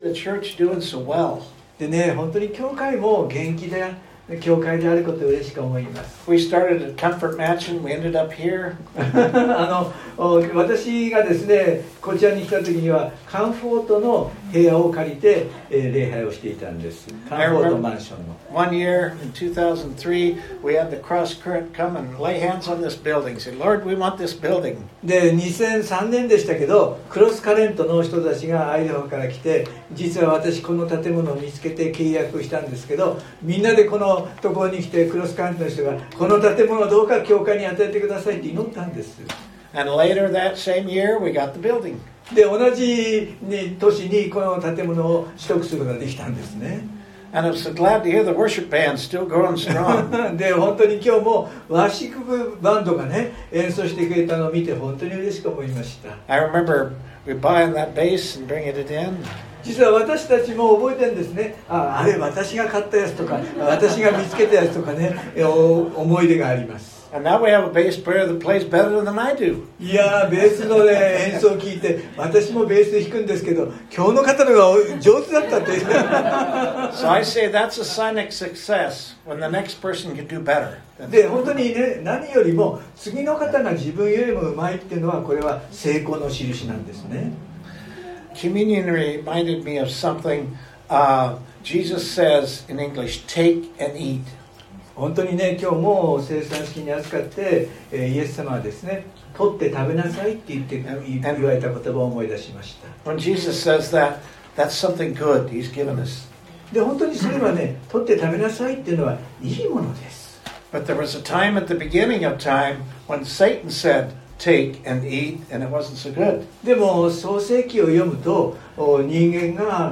↓メッセージが聞けます。（日曜礼拝録音）【iPhoneで聞けない方はiOSのアップデートをして下さい】コリント人への手紙第一 15:3-8 3 わたしがあなたがたに伝えた最も大切なことは、わたし自身も受けたことですが、それは、キリストが聖書...